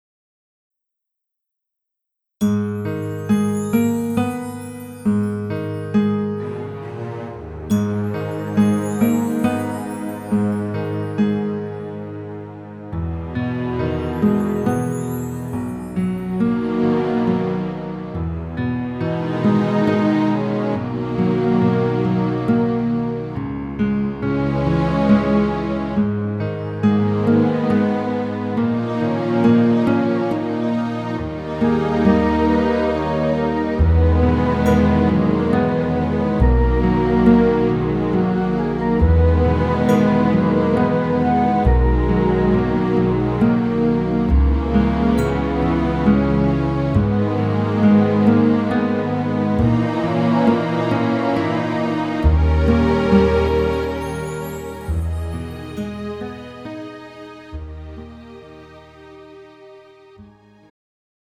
음정 남자키
장르 가요 구분 Pro MR